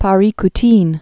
(pä-rēk-tēn)